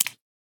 1.21.5 / assets / minecraft / sounds / entity / fish / flop3.ogg
flop3.ogg